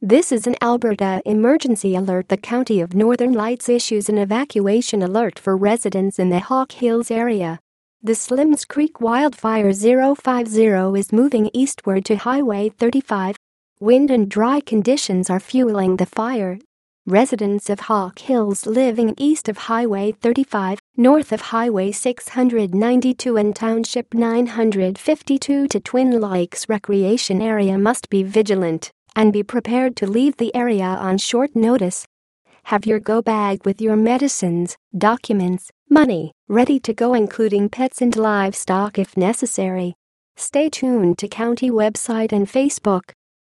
Broadcast Audio